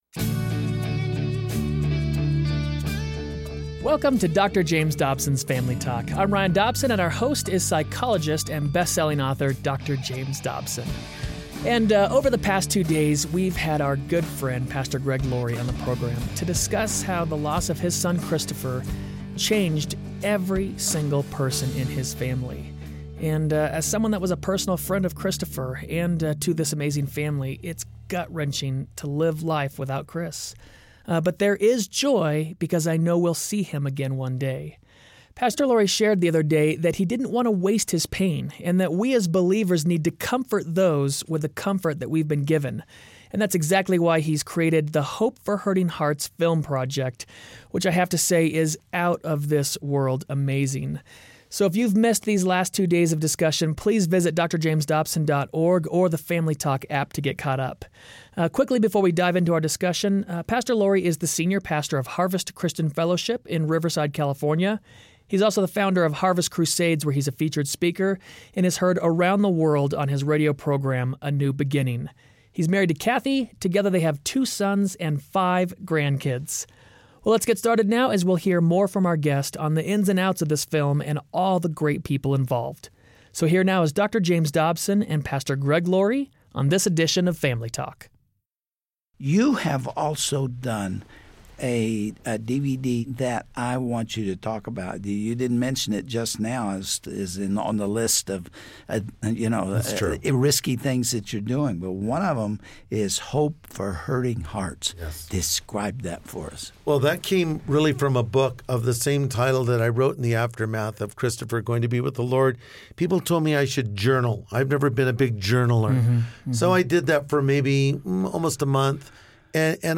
Hear from a man doing just that! Pastor Greg Laurie discusses a film project that was born out of the pain he endured, and created to bring hope to hurting hearts.